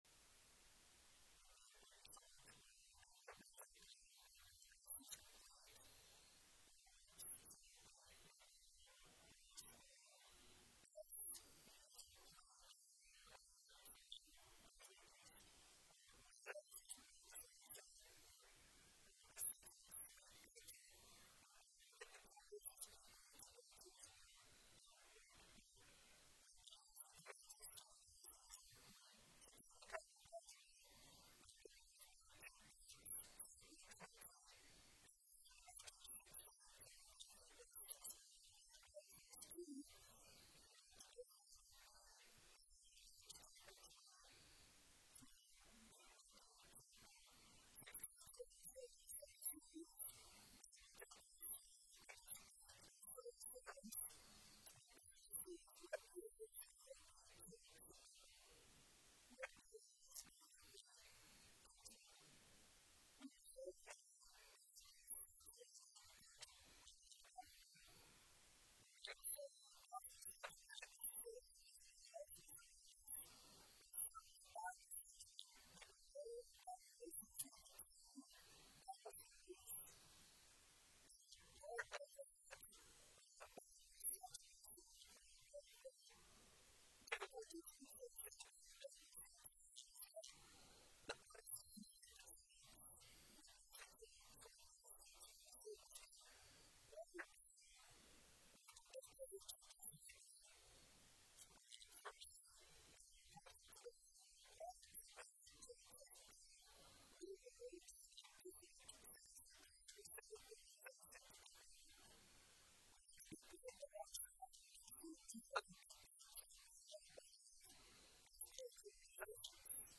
Sermon Series: What is a Healthy Church Member?